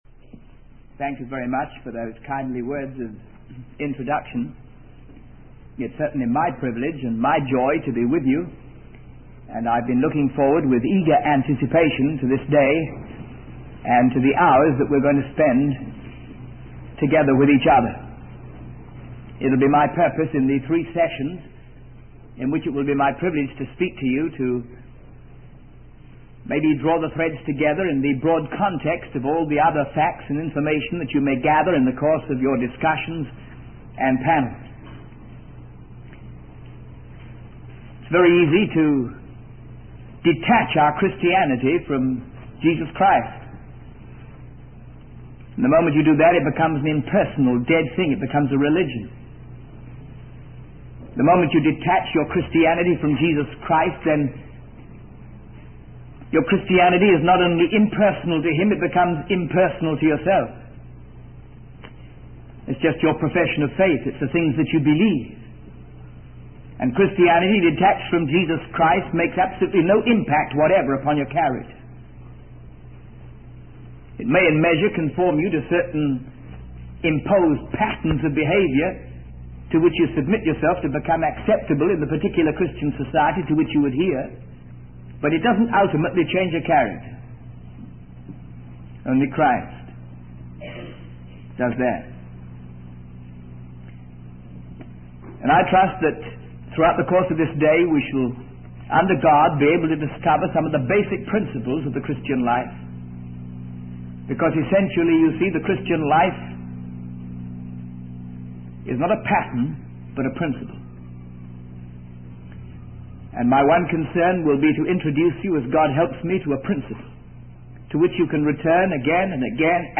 In this sermon, the speaker reflects on his own journey of understanding the true meaning of the Christian life.